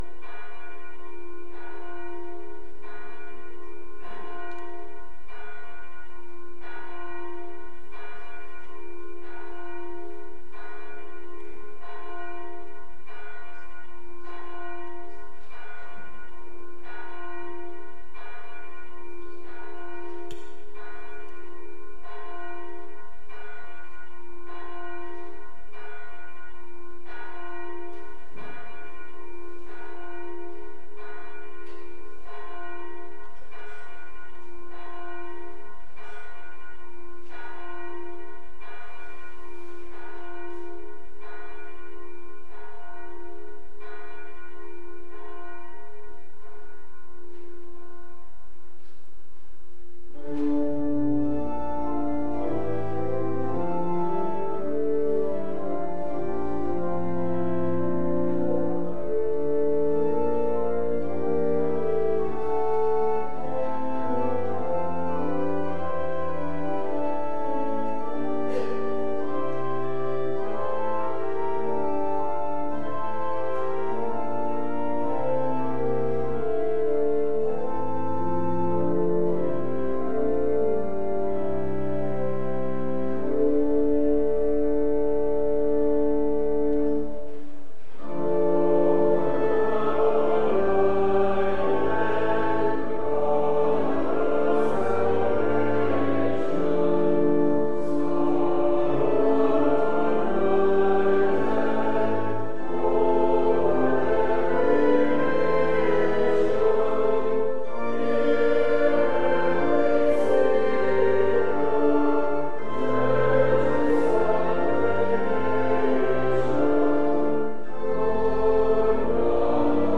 LCOS Worship Service